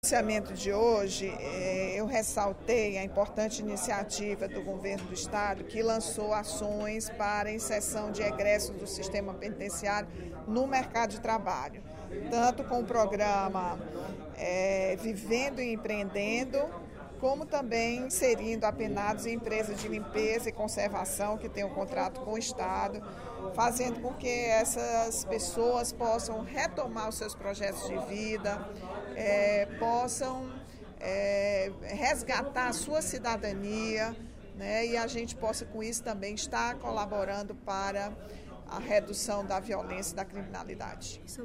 A deputada Rachel Marques (PT) ressaltou, no primeiro expediente da sessão plenária da Assembleia Legislativa desta quinta-feira (05/11), mais uma ação do programa Ceará Pacífico. A parlamentar citou o convênio do Governo do Estado com o Tribunal de Justiça para inserir apenados e egressos do sistema penitenciário no mercado de trabalho.